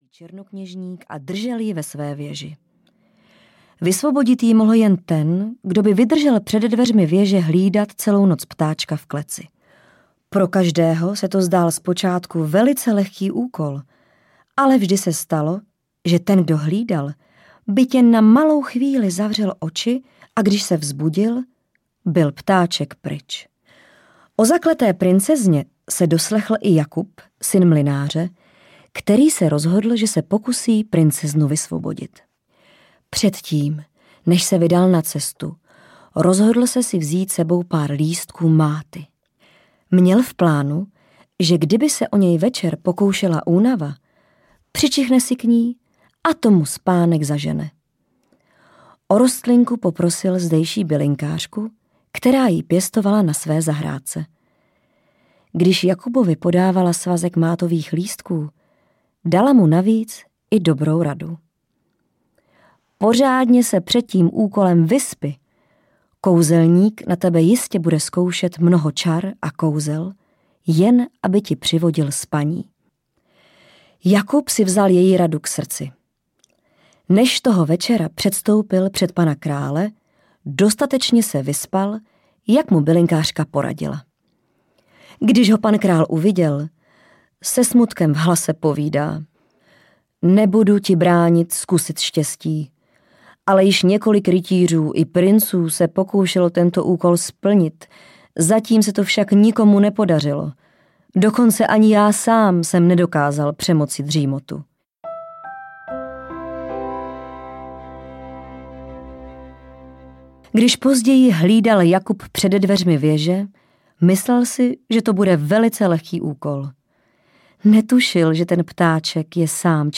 Bylinkové pohádky audiokniha
Ukázka z knihy